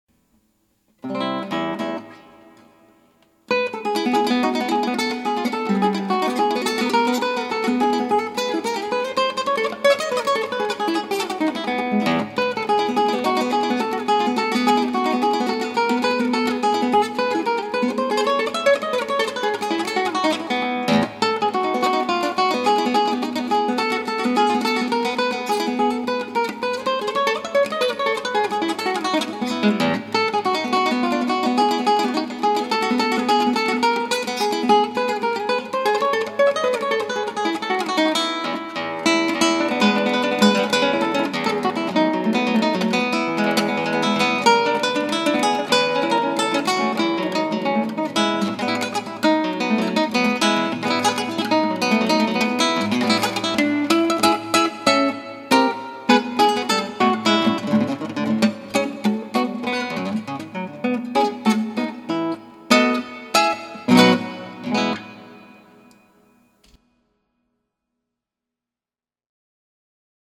クラシックギター 「デンジャー・・・」 - 「ソルの魔笛ラスト」 F Sor
ギターの自演をストリーミングで提供
かなりデンジャーです。
そんで疲れきったときにやけくそで入れたのでデンジャーです。